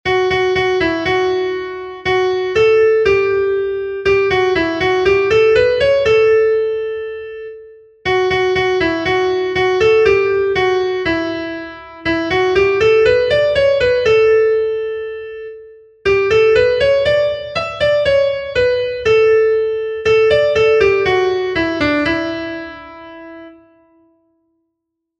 Gabonetakoa
A-B-C-D